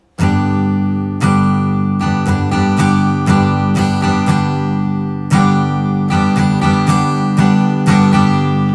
3. Schlagmuster für Gitarre